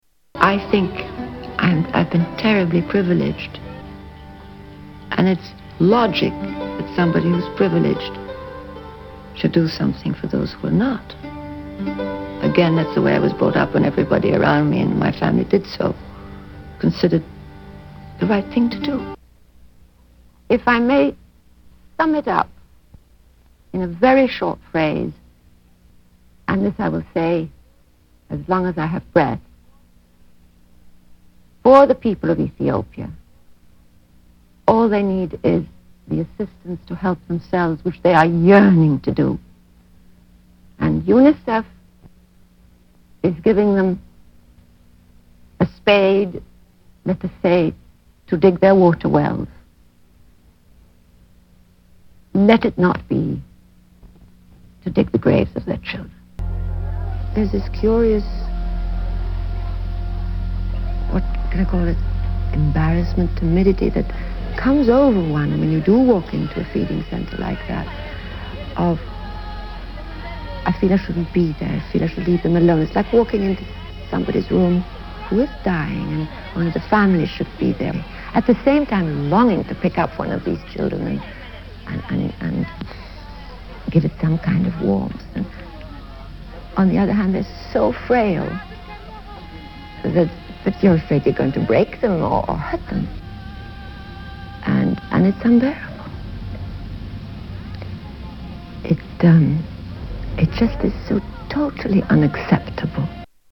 Audrey Hepburn interview about UNICEF
Tags: Audrey Hepburn clips Audrey Hepburn interview Audrey Hepburn audio Audrey Hepburn Actress